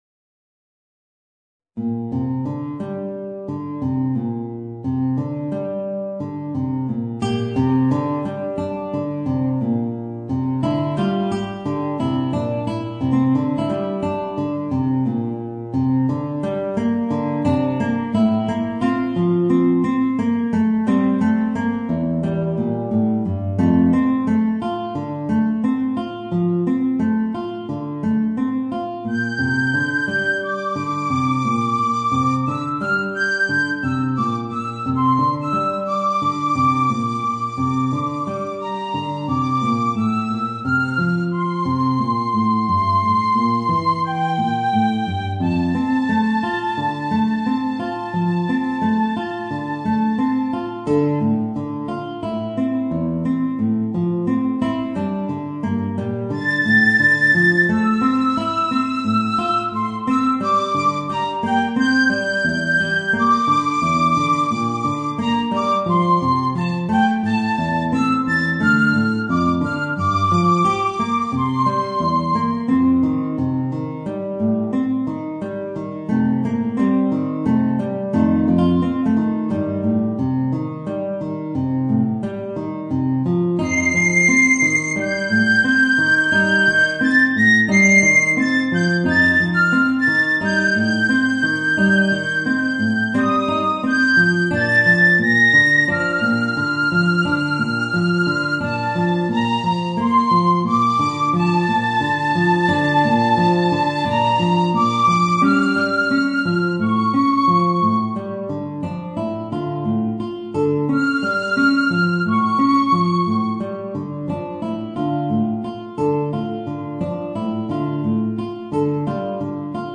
Voicing: Guitar and Piccolo